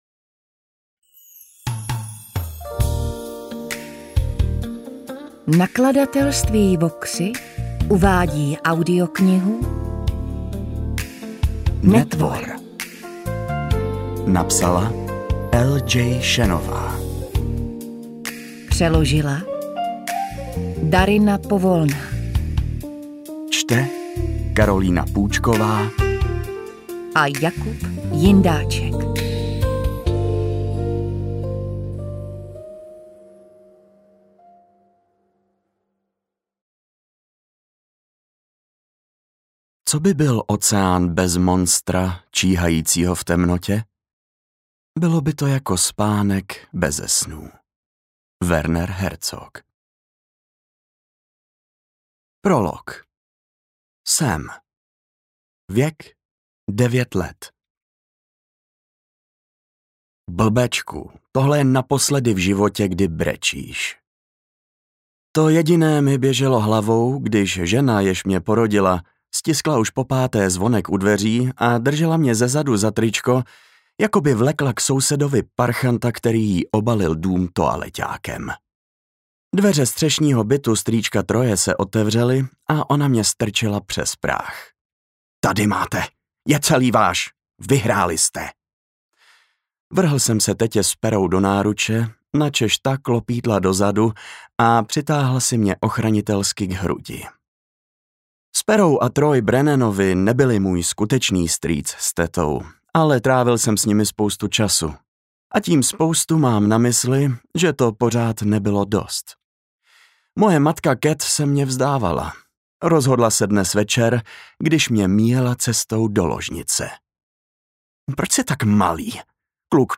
AudioKniha ke stažení, 31 x mp3, délka 14 hod. 40 min., velikost 802,2 MB, česky